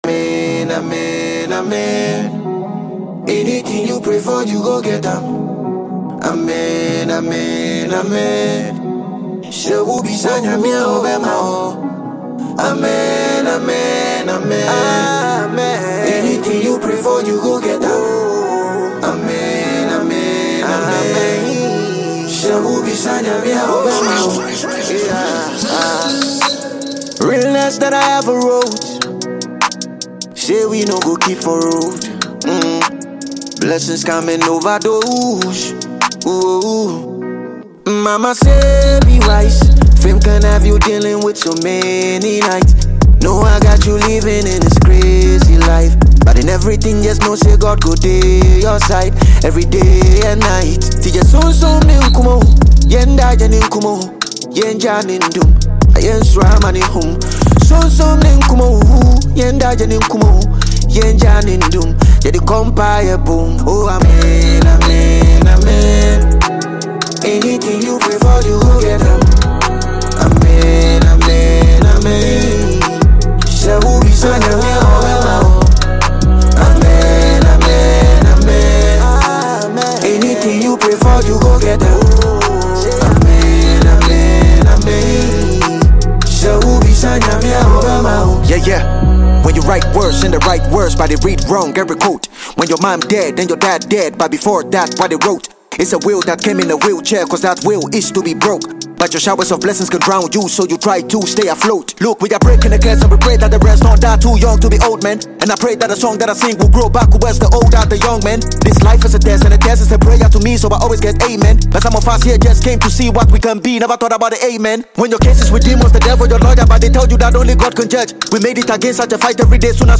Ghana MusicMusic
Ghanaian rapper